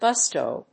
音節gus・to 発音記号・読み方
/gˈʌstoʊ(米国英語), ˈɡʌstəʊ(英国英語)/